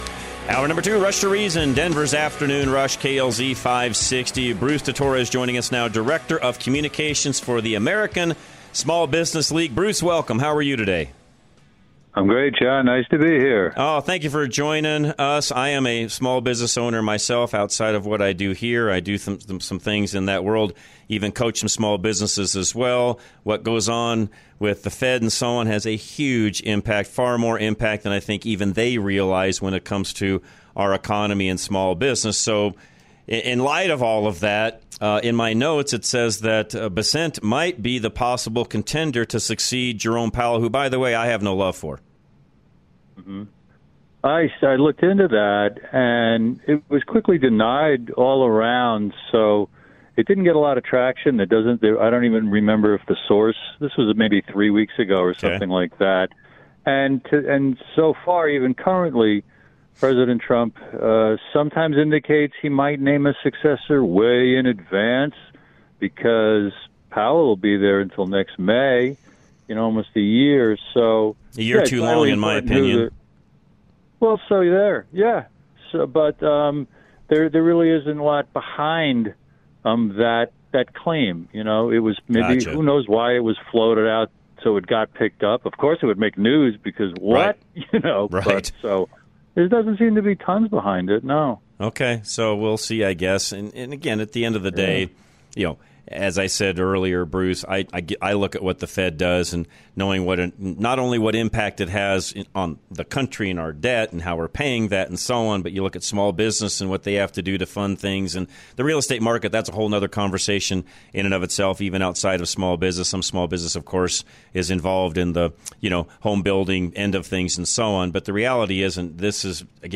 Rush To Reason - Interviews Are Small Businesses Being Shut Out of Billions?